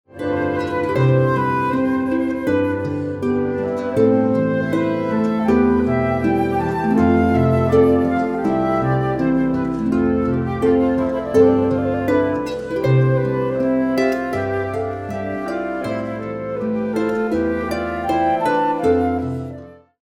flute and harp